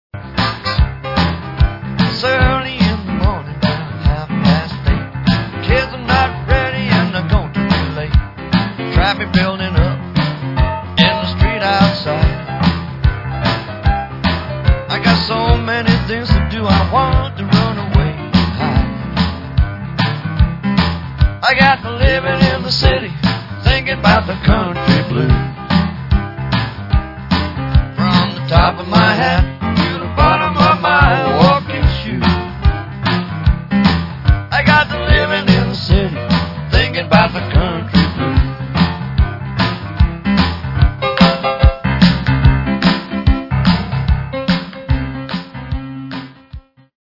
A LITTLE BOOGIE TO START THE DAY WITH